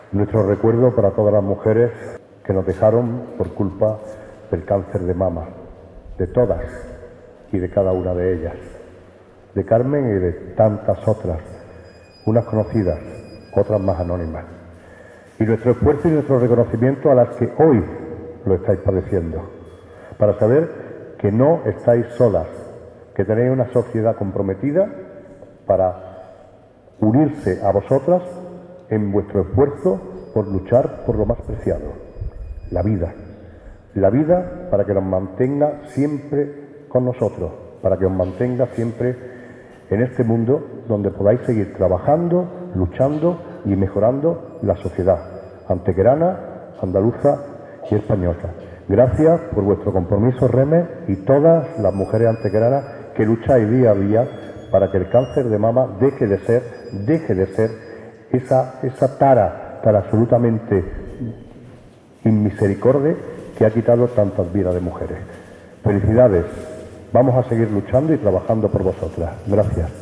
El alcalde Manolo Barón, presente en dicho acto, mostraba unas emocionadas palabras de recuerdo "a todas las mujeres que nos dejaron por culpa del cáncer de mama", lanzando además un mensaje de ánimo y optimismo a todas aquellas que lo padecen en la actualidad.
Cortes de voz